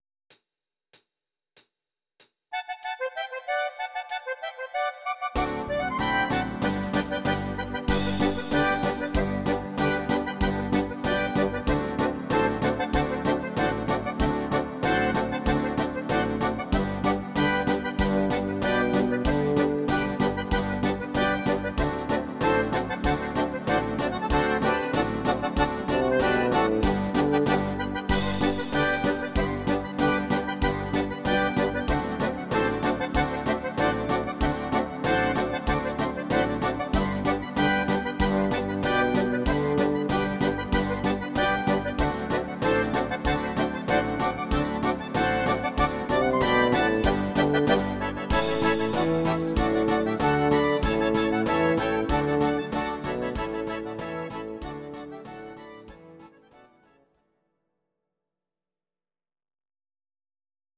These are MP3 versions of our MIDI file catalogue.
instr. Akkordeon